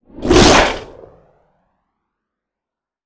bullet_flyby_designed_02.wav